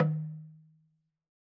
LogDrumHi_MedM_v3_rr2_Sum.wav